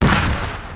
Kick 9.wav